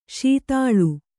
♪ śitāḷu